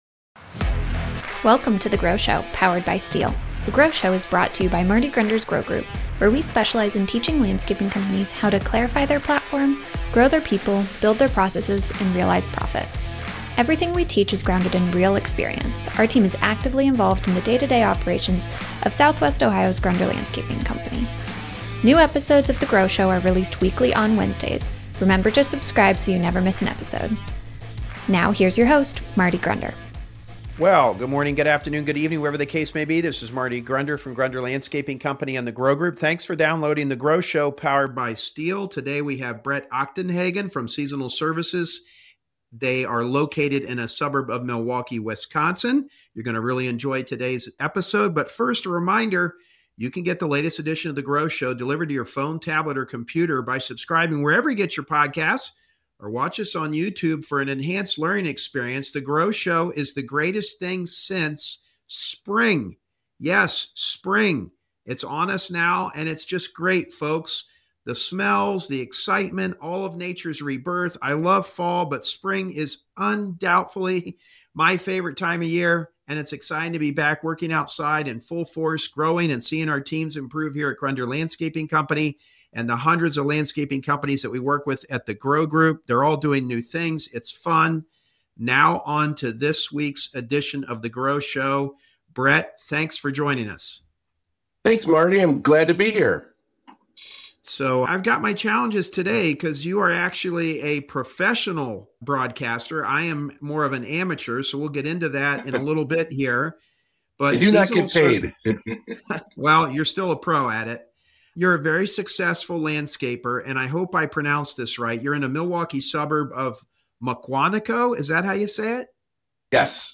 Interview Series